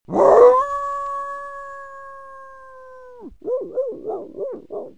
Dog Howl Sound Button - Free Download & Play